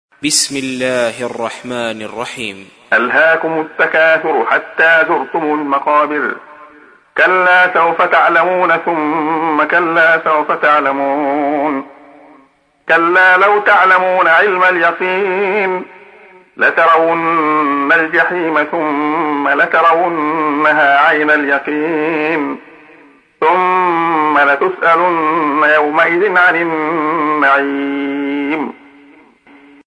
تحميل : 102. سورة التكاثر / القارئ عبد الله خياط / القرآن الكريم / موقع يا حسين